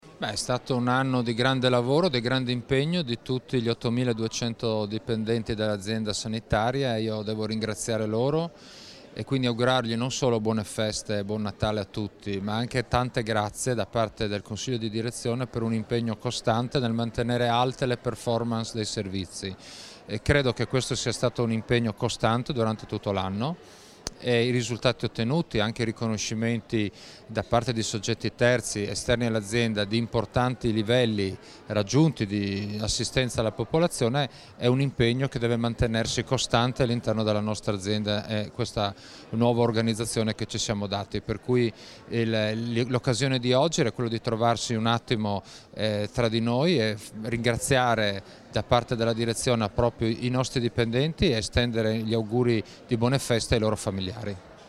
Natale 2018, oggi il tradizionale scambio degli auguri in Apss